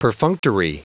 Prononciation du mot perfunctory en anglais (fichier audio)
Prononciation du mot : perfunctory
perfunctory.wav